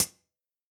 UHH_ElectroHatD_Hit-19.wav